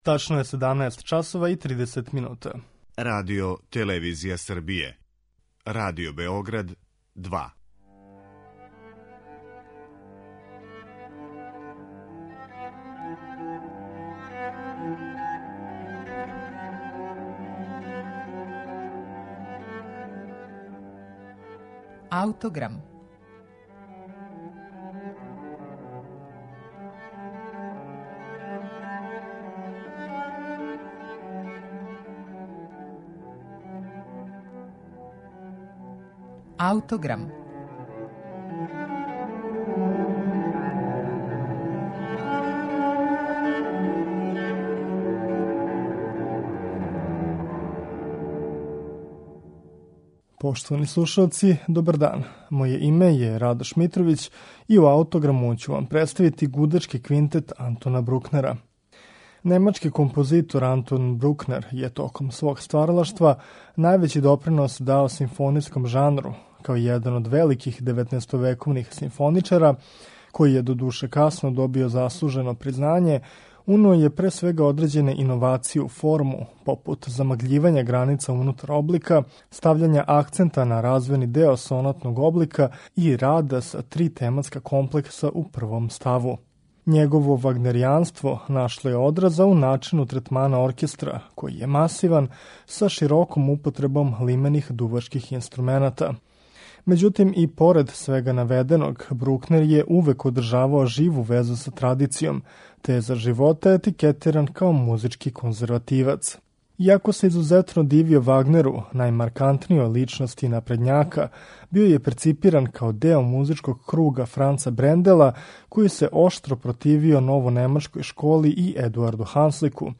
Слушаћемо Гудачки квинтет у Еф-дуру Антона Брукнера
квартета